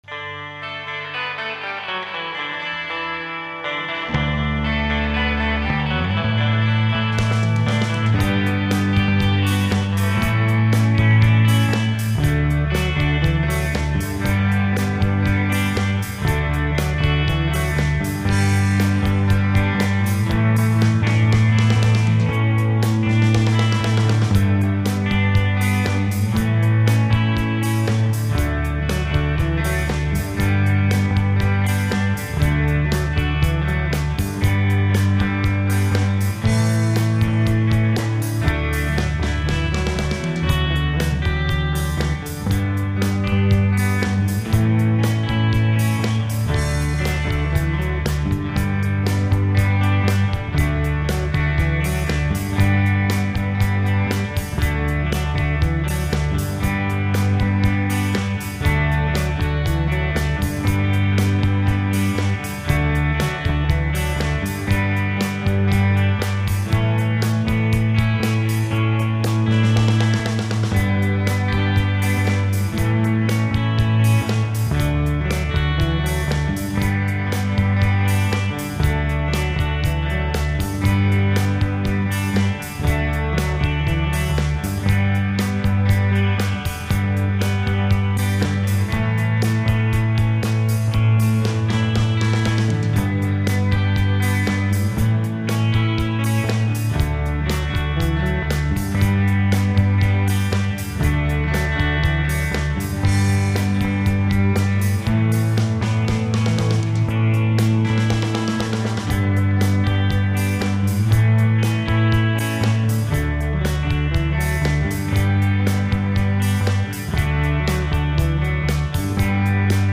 I tabbed 3 guitars and the bass.